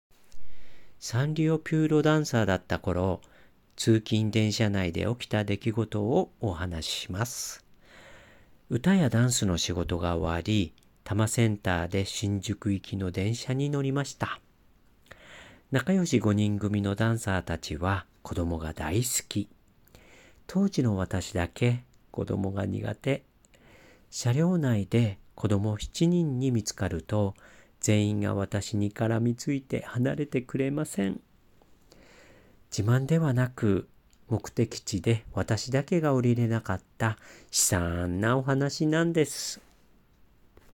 クリック Voice